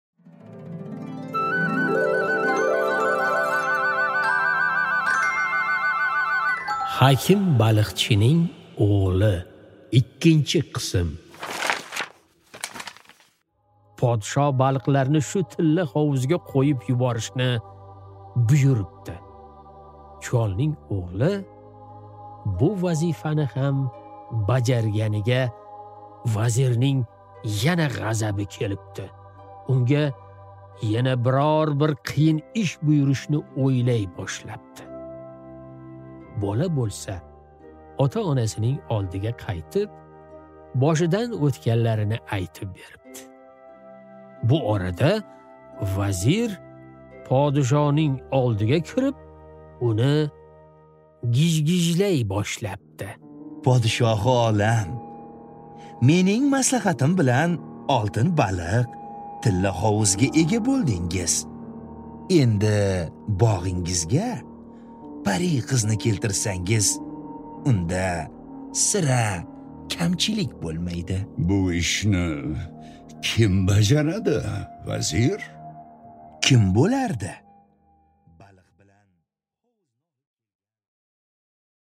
Аудиокнига Hakim baliqchining o'g'li 2-qism